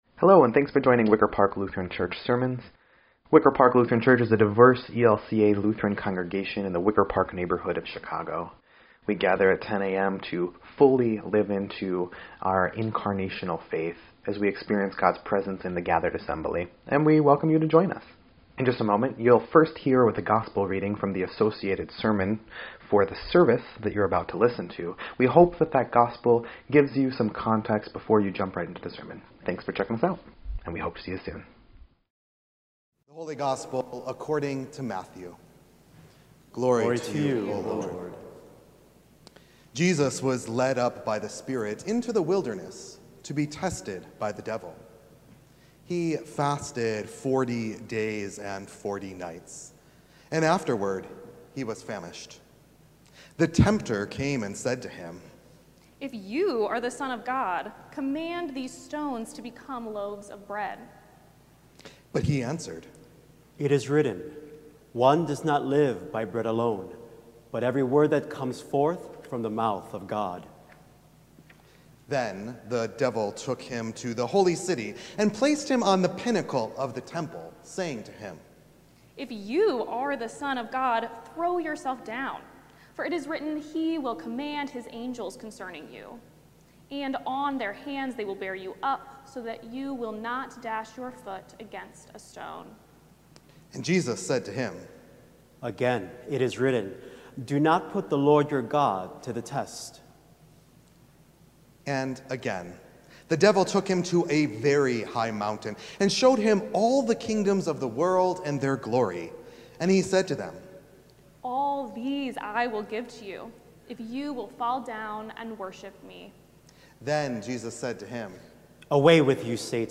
2.22.26-Sermon_EDIT.mp3